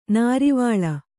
♪ nārivāḷa